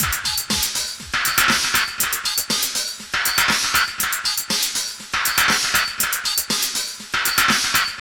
15.5 LOOP4.wav